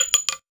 weapon_ammo_drop_09.wav